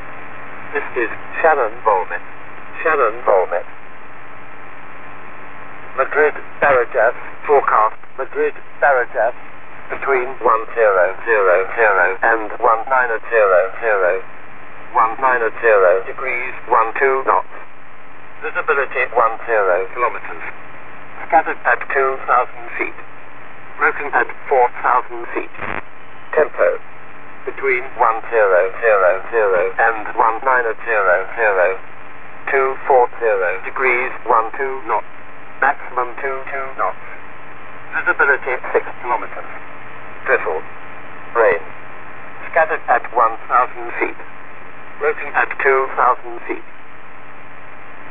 Pour les liaisons aériennes a grandes distances il existe des stations VOLMET en bande HF reparties sur tout le globe.
Ce sont des messages vocaux synthétisés transmis en continu et remis a jour lors d'évolutions significatives des conditions météorologiques.